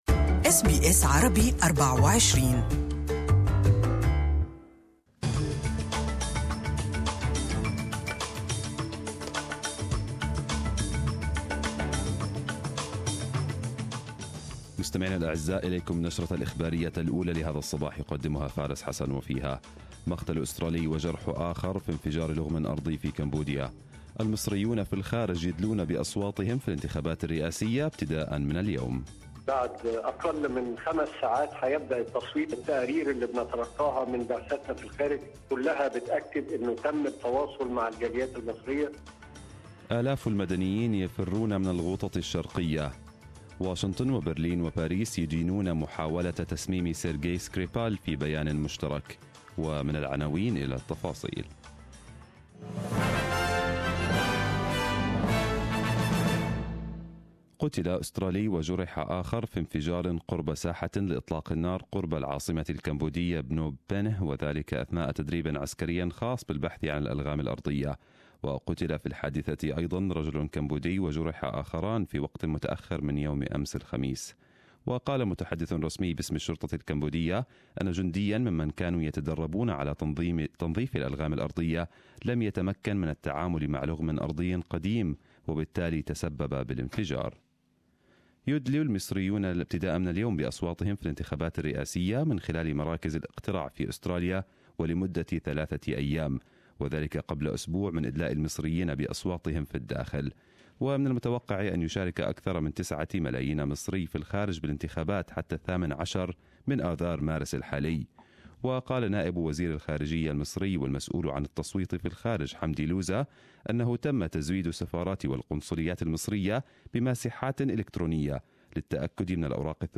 Arabic News Bulletin 16/03/2018